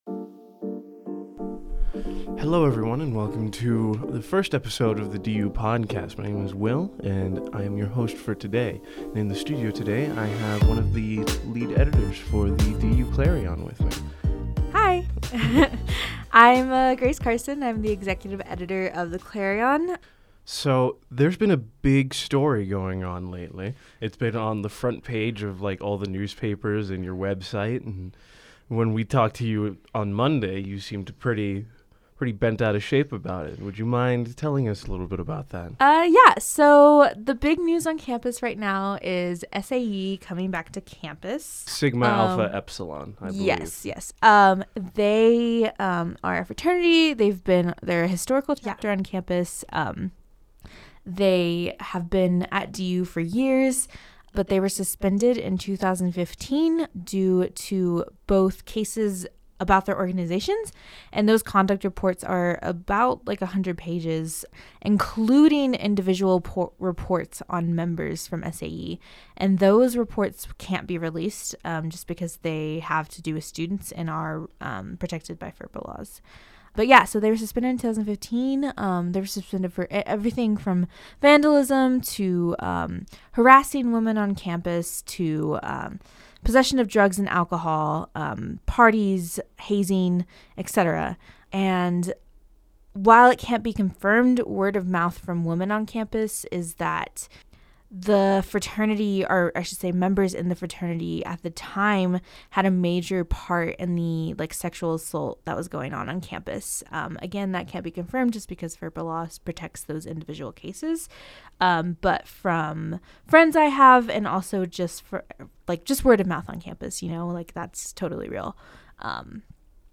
The DU Clarion Background music